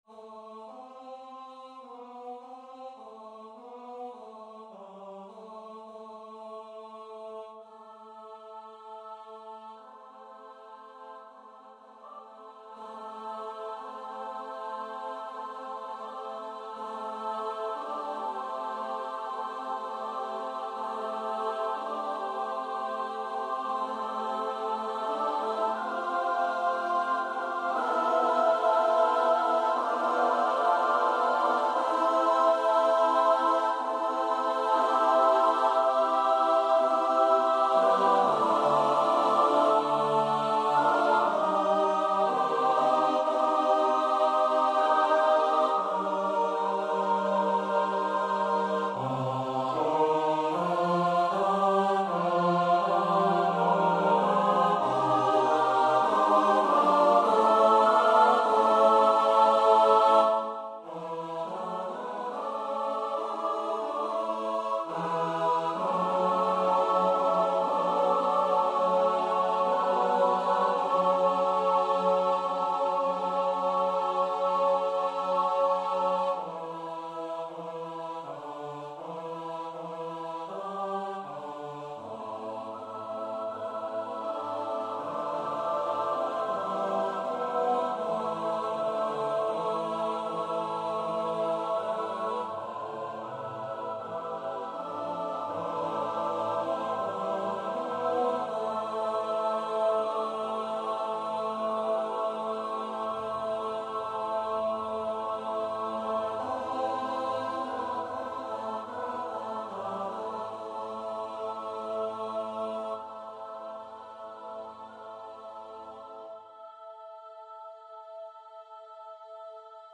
Ensemble: double SATB unaccompanied
MP3 performance (Sibelius Sounds, prepared by the compsoer)